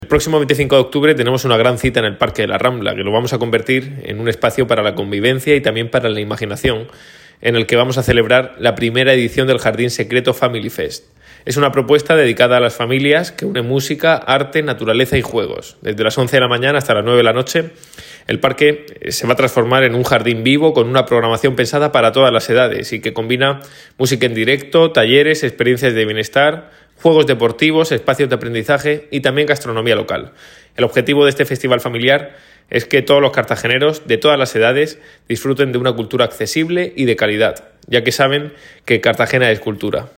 Enlace a Declaraciones de Ignacio Jáudenes